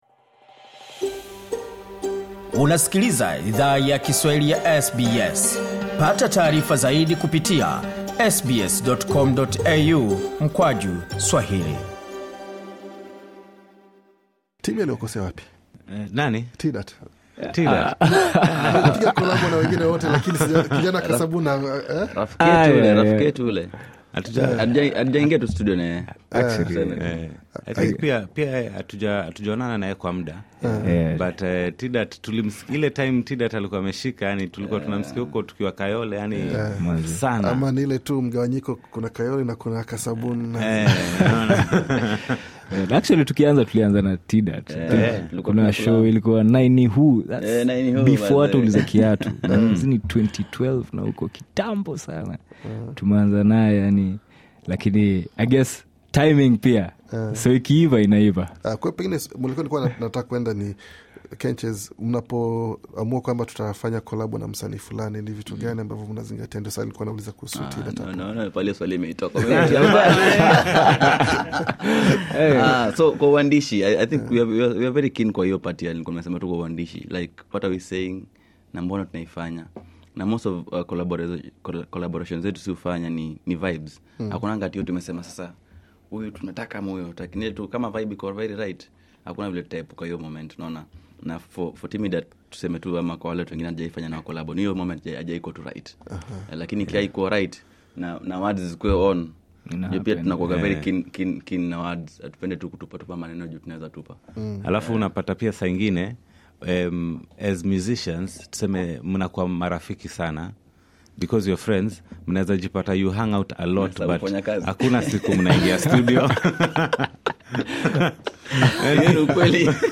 Wanachama wa H_art the Band, walitembelea studio ya SBS Swahili kwa mahojiano maalum kabla ya tamasha yao jijini Sydney. Wasanii hao walifunguka kuhusu mapokezi waliyo pata nchini Australia pamoja na maandalizi ya tamasha zao.